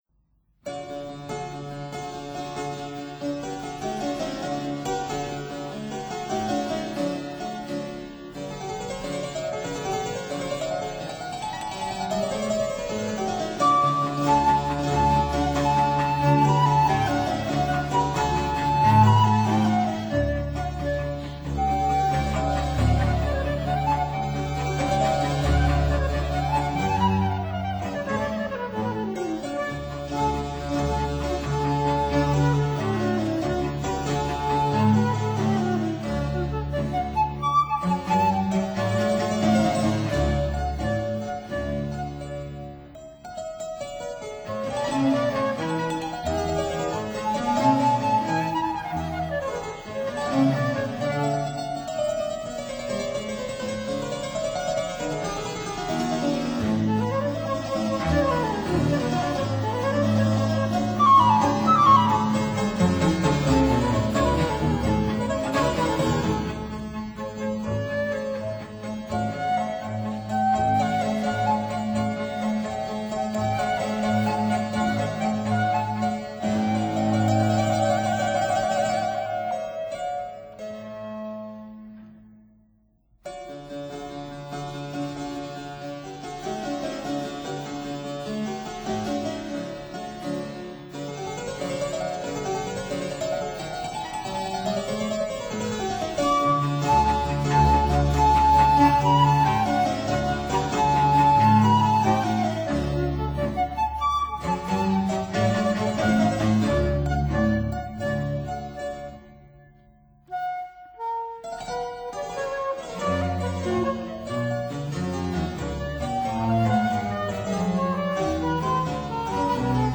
Fortepiano
(Period Instruments)